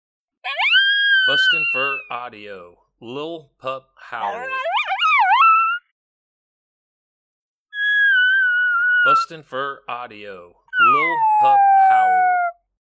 Coyote Puppy lost and lonely howling.
BFA Lil Pup Howl Sample.mp3